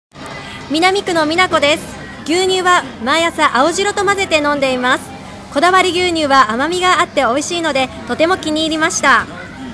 試飲いただいたお客様の生の声
リンクをクリックするとこだわり牛乳を試飲いただいた皆様からの感想を聞くことができます。
4月8日（火）15:00～18:00　ビッグハウス 新川店
お客様の声7